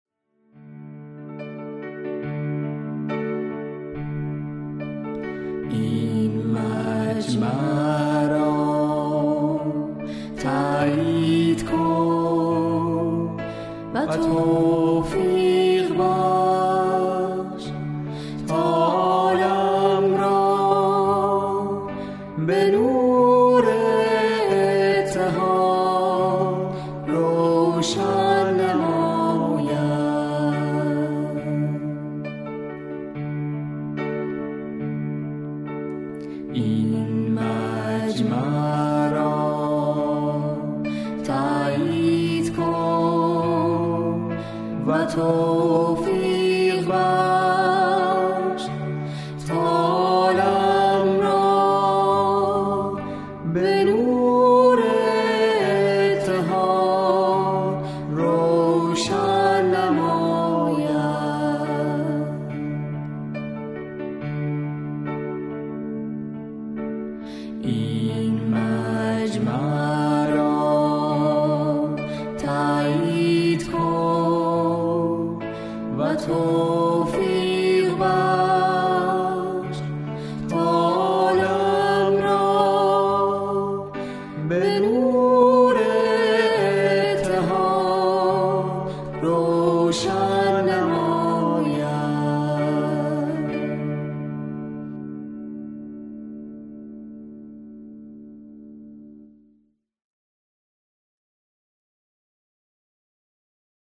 دعا و نیایش با موسیقی